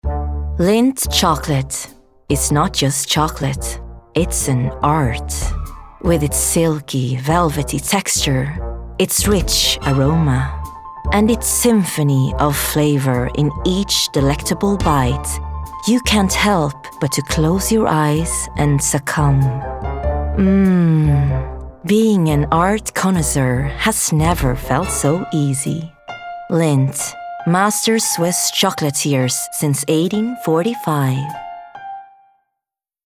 Lindt - Distinctive, Luxury, Engaging